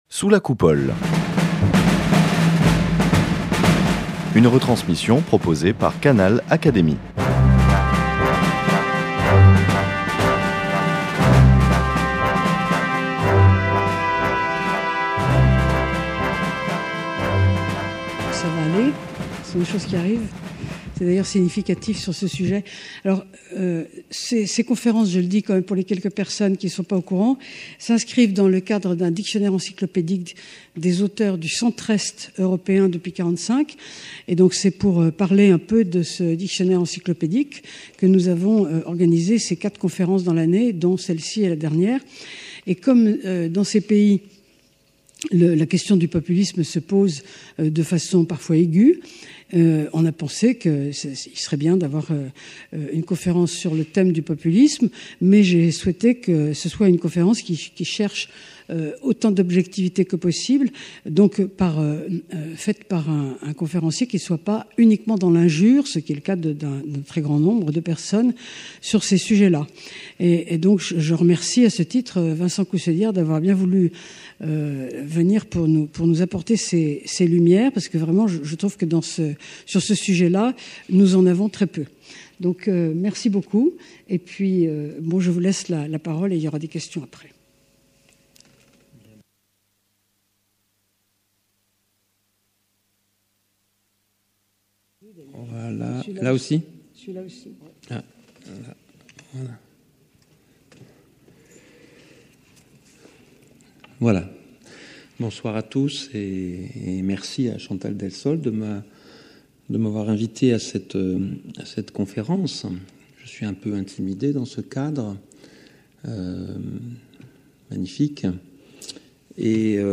Conférence
à l’Académie des sciences morales et politiques